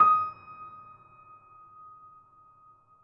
piano-sounds-dev
Steinway_Grand